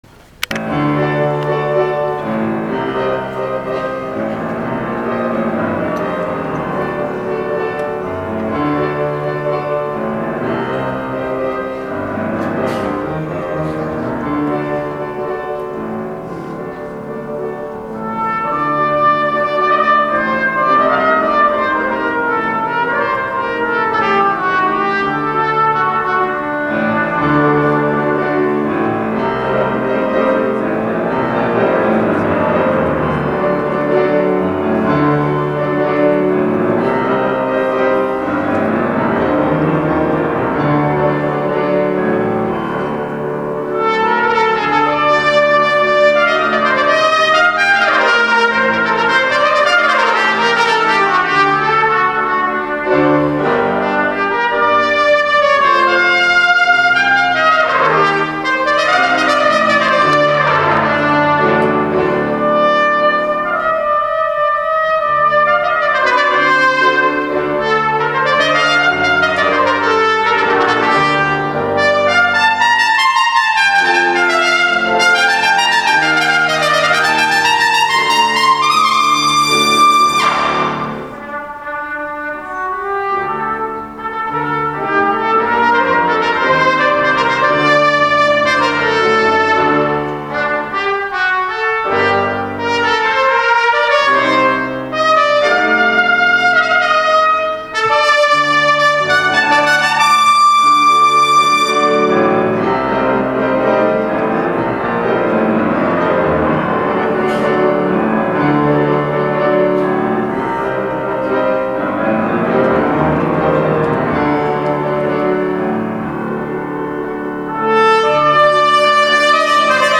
trompeta